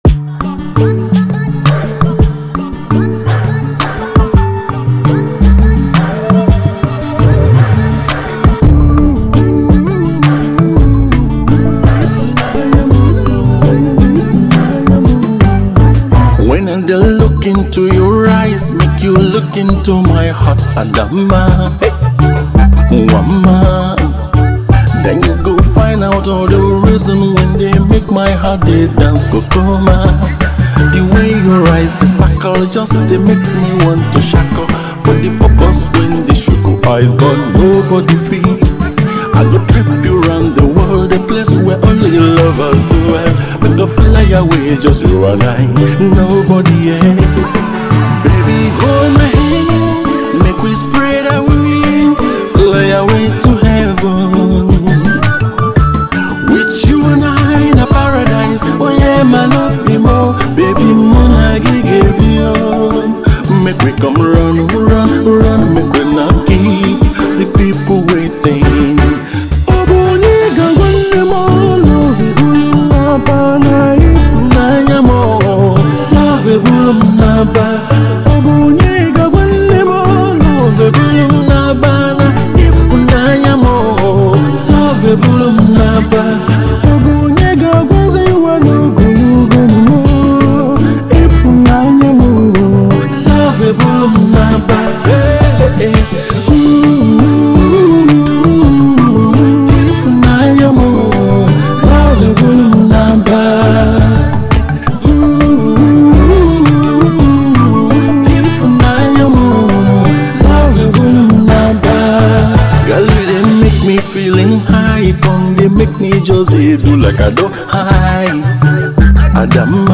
Fast rising singer